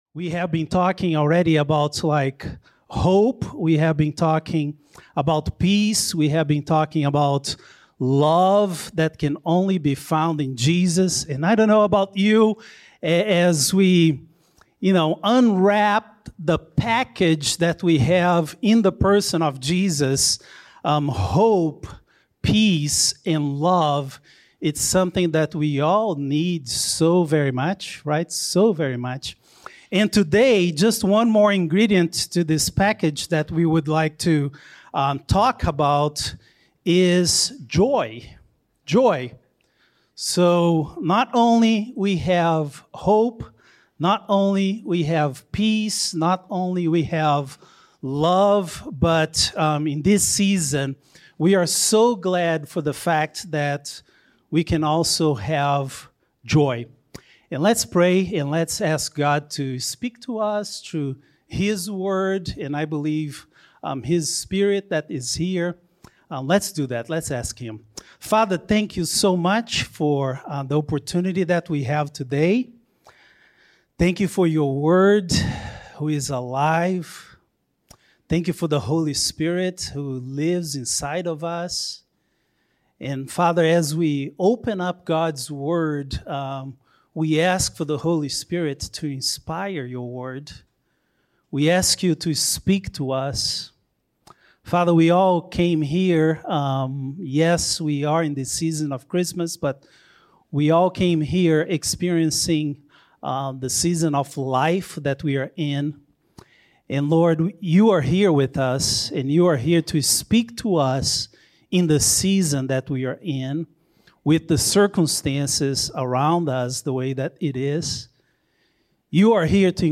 Sermons | Good News Church Georgia